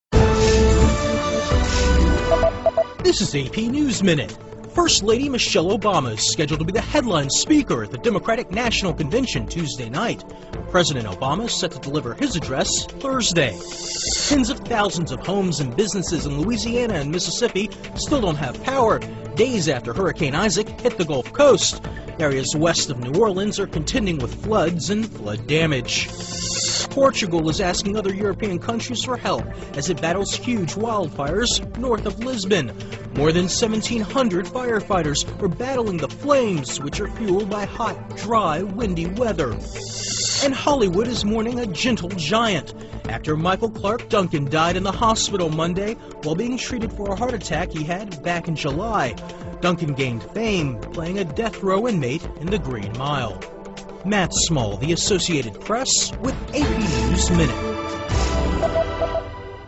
在线英语听力室美联社新闻一分钟 AP 2012-09-07的听力文件下载,美联社新闻一分钟2012,英语听力,英语新闻,英语MP3 由美联社编辑的一分钟国际电视新闻，报道每天发生的重大国际事件。电视新闻片长一分钟，一般包括五个小段，简明扼要，语言规范，便于大家快速了解世界大事。